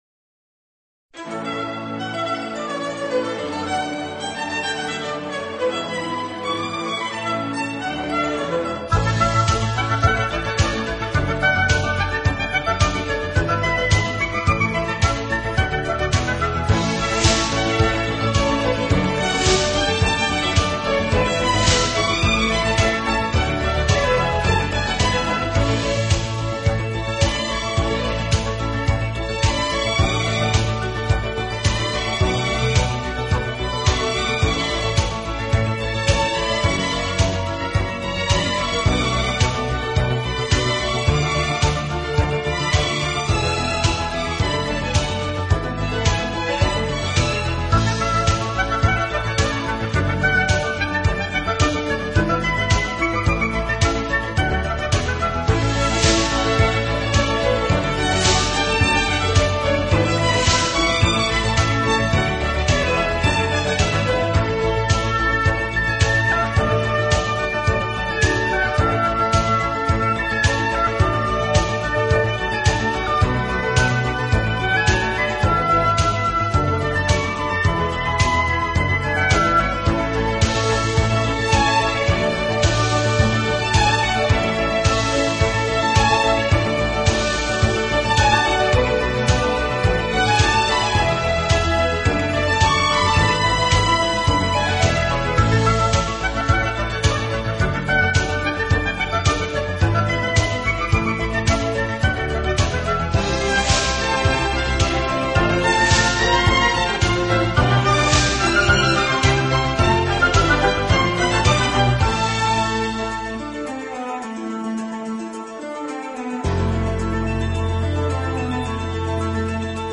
音乐类型：跨界融合 fusion
音乐风格：neo classical,室内乐